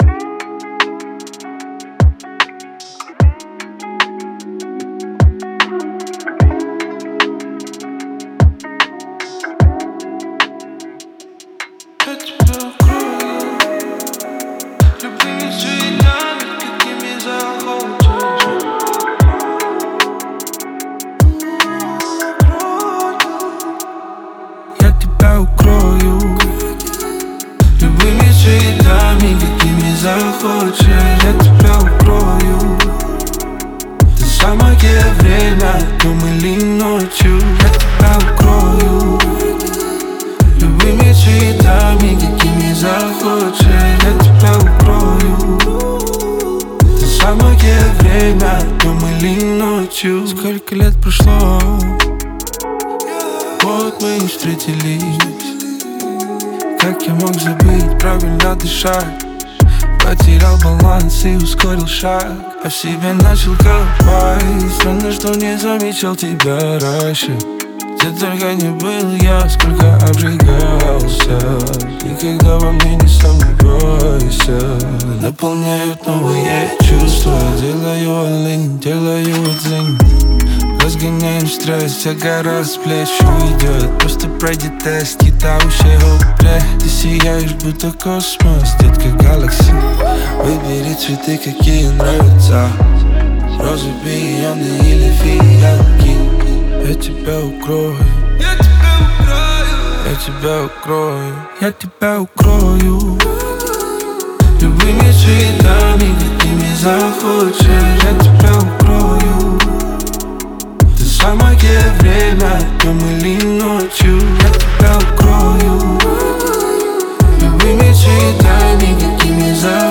Категория Рэп